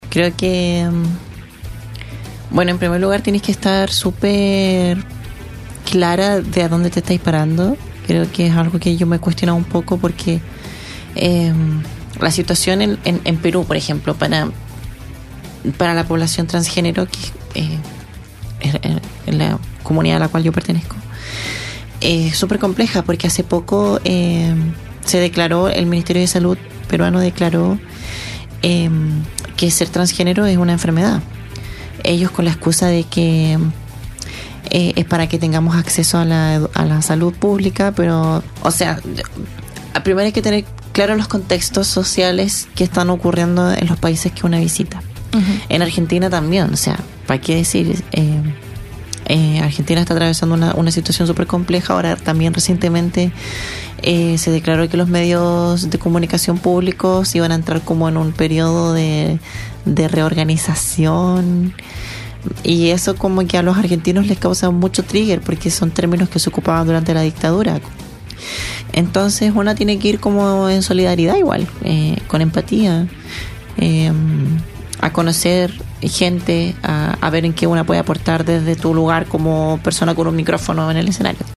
cantautora y artista escénica
el ritmo latino se mezcla con el sentimiento a flor de piel